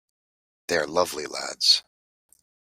Pronounced as (IPA) /lædz/